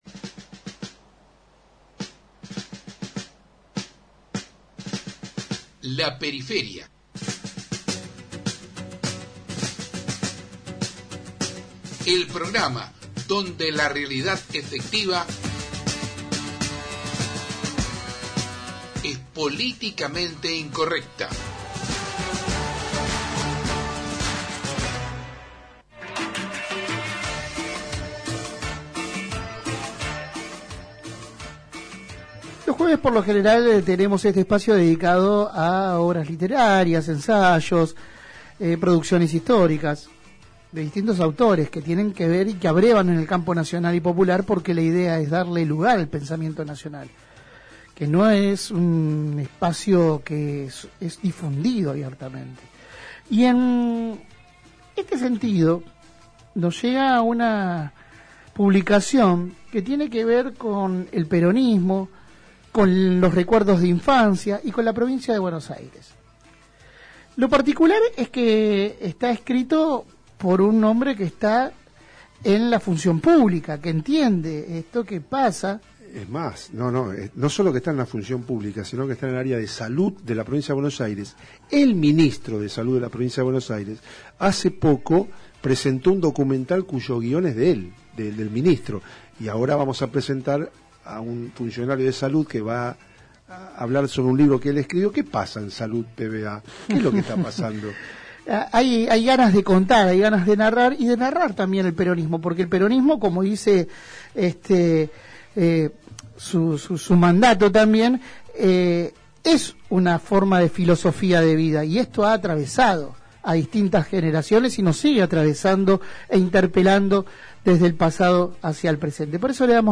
Compatimos la entrevista completa: 03/11/2022